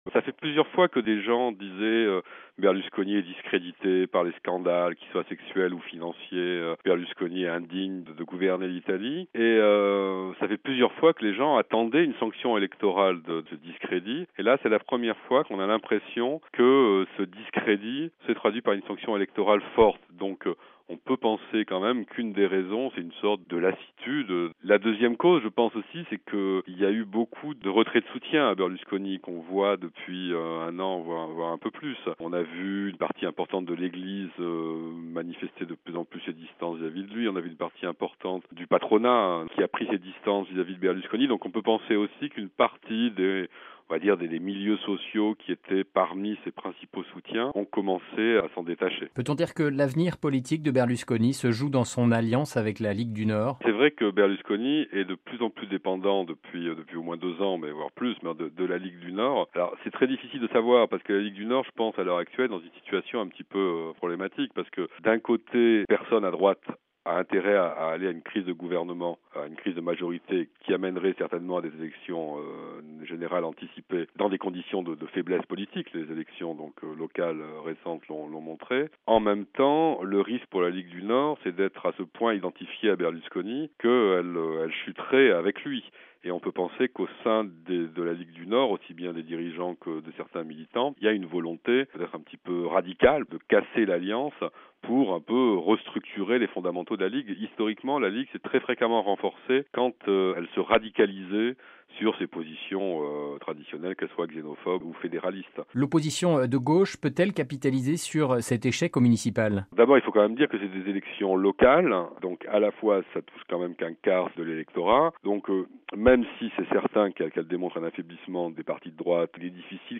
Dossier : Silvio Berlusconi ne se dit pas fini